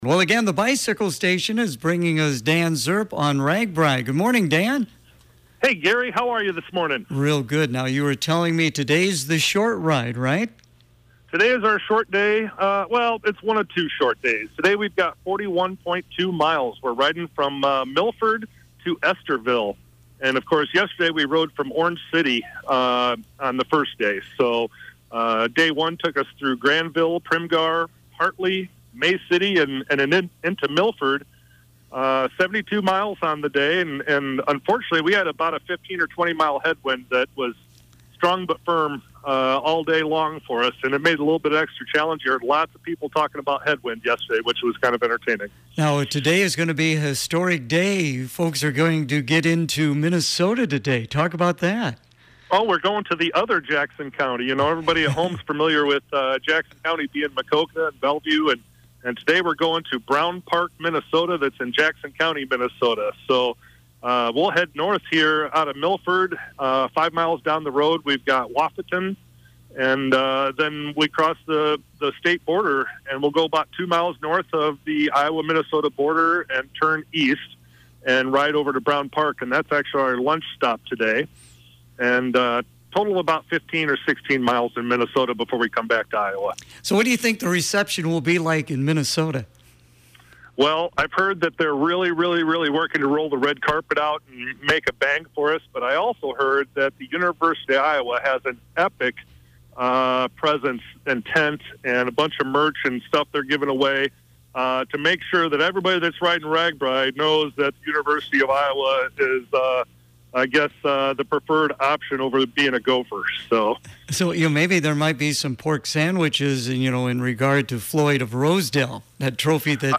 Clinton County Supervisor Dan Srp is once again riding on Ragbrai this year and is keeping listeners informed about this unique Iowa event.
If you missed it on the air, todays report is posted below.